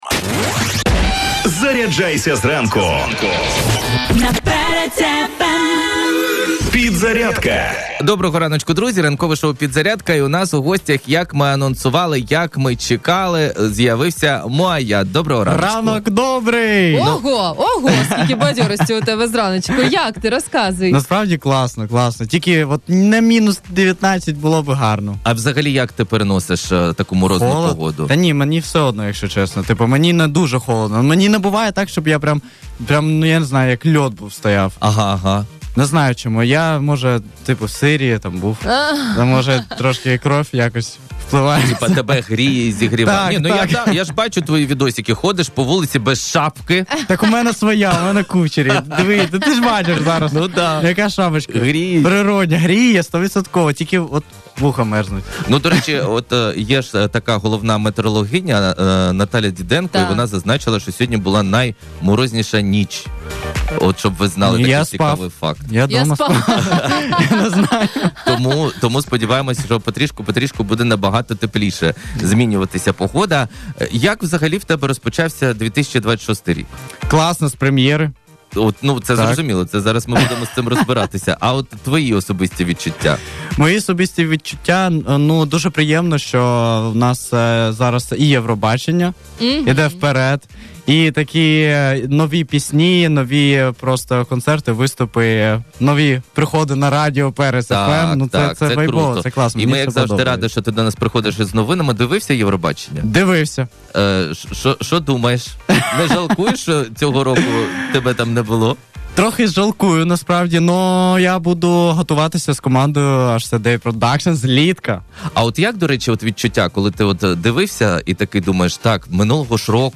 У ранковому шоу «Підзарядка» на радіо Перець FM відбулася яскрава музична прем’єра.